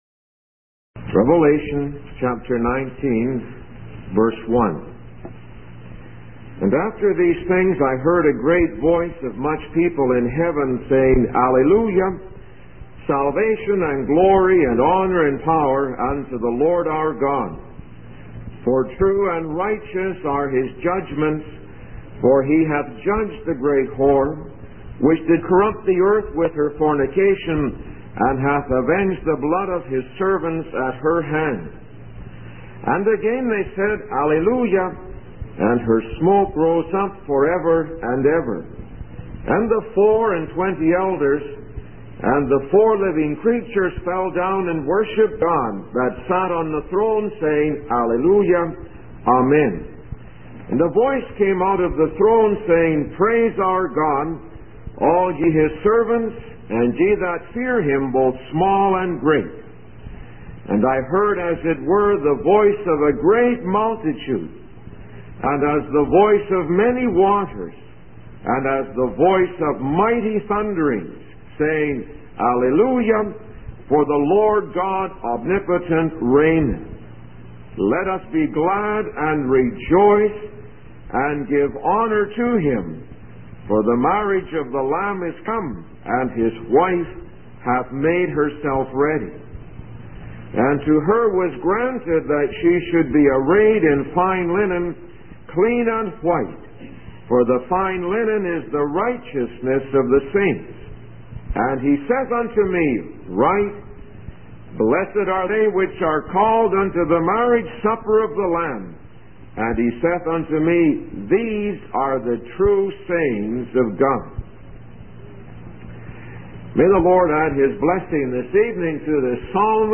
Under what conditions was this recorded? Week of Meetings 1974-06